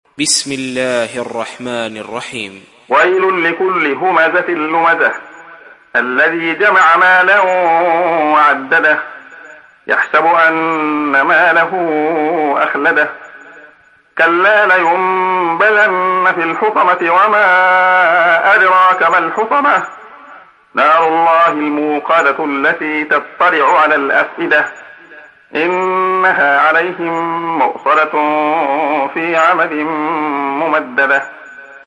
تحميل سورة الهمزة mp3 بصوت عبد الله خياط برواية حفص عن عاصم, تحميل استماع القرآن الكريم على الجوال mp3 كاملا بروابط مباشرة وسريعة